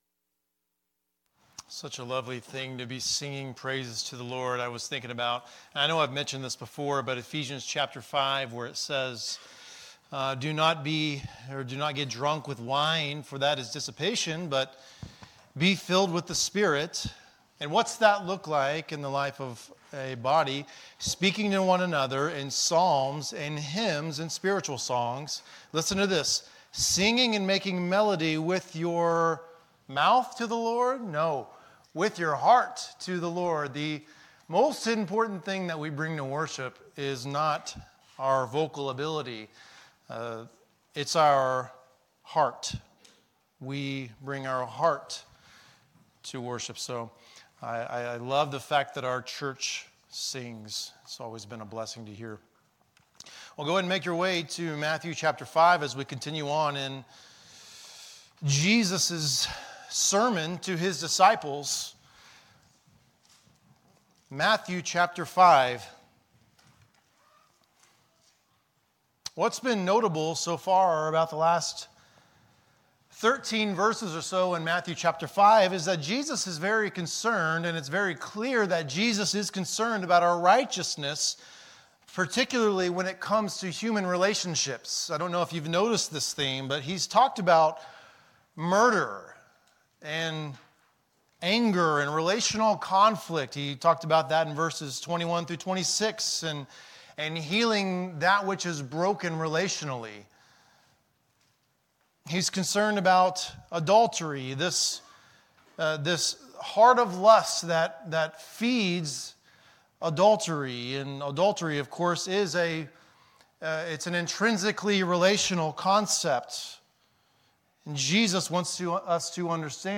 Jesus Loved Them to the End | SermonAudio Broadcaster is Live View the Live Stream Share this sermon Disabled by adblocker Copy URL Copied!